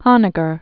(hŏnĭ-gər, hōnĕgər, ô-nĕ-gĕr), Arthur 1892-1955.